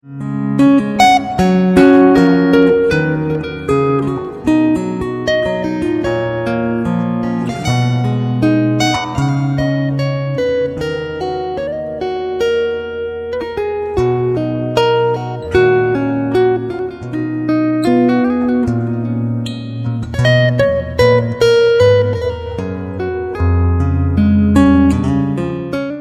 Two hand tapping specialist